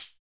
NOISE CHH 2.wav